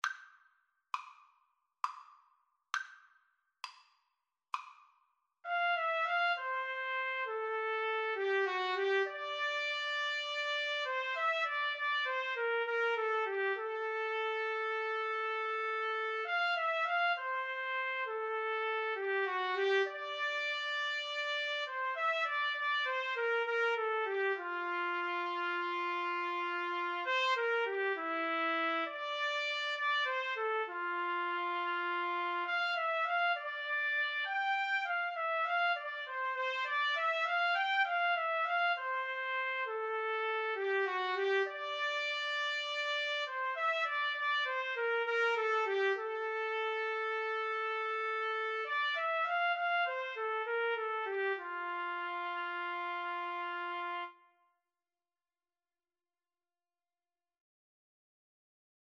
Moderato
9/8 (View more 9/8 Music)